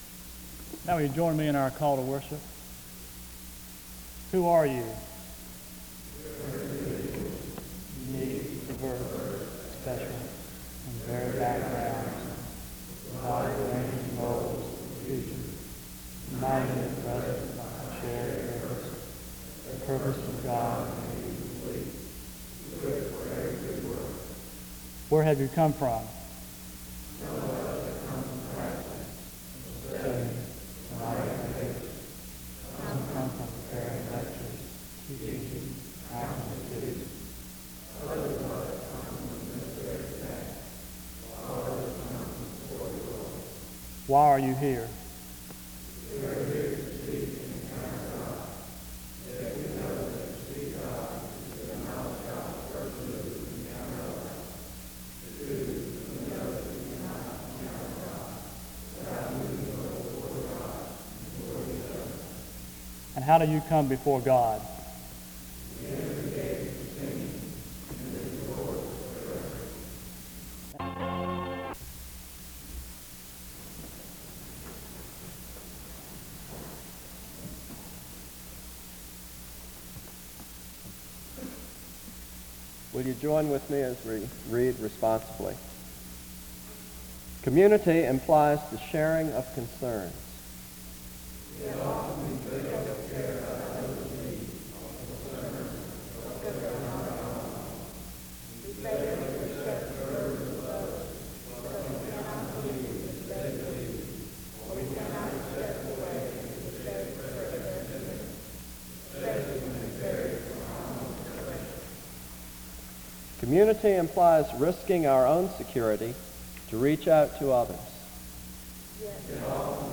Download .mp3 Description The service begins with a responsive reading from 0:00-4:13. 1 Corinthians 12:24-27 is read from 4:18-4:53. The leaders of the Student Coordinating Council share words of appreciation and lessons from 5:04-21:46. The service closes with a responsive reading and prayer from 21:51-23:12.